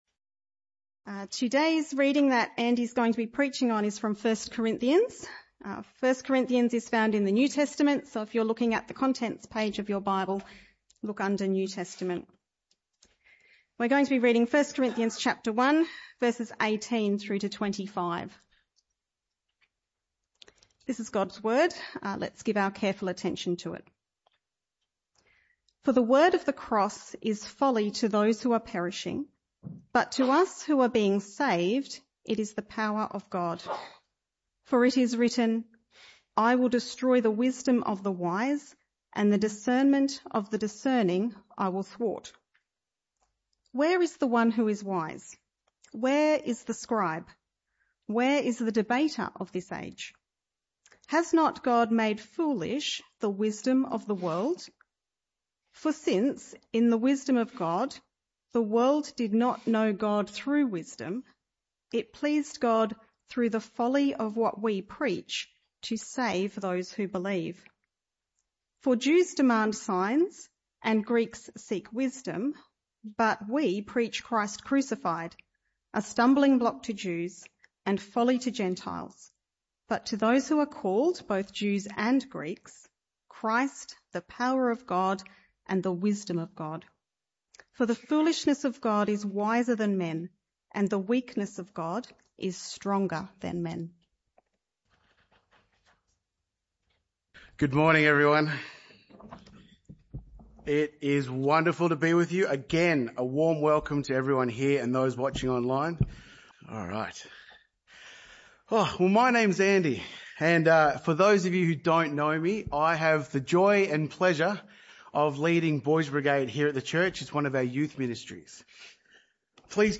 This talk was a one-off talk in the AM Service.
1 Corinthians 1:18-25 Service Type: Morning Service This talk was a one-off talk in the AM Service.